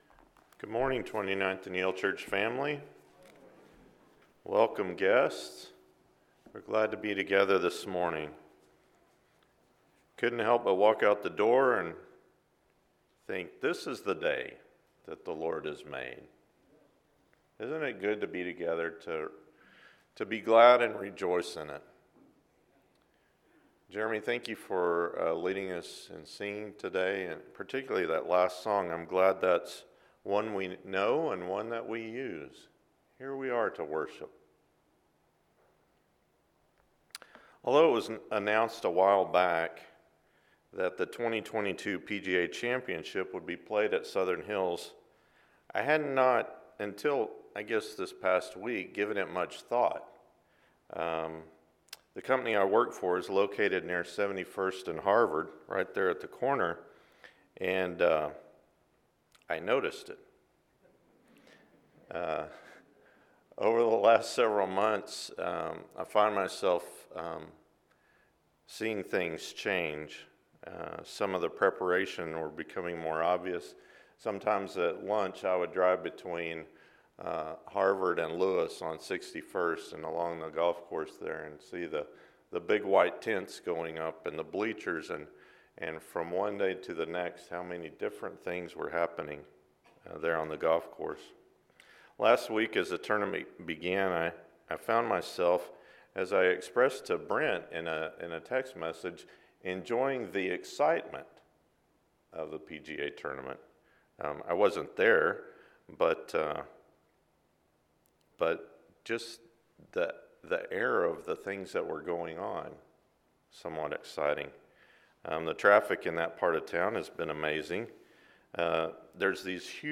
More to the Story: Barnabas – Sermon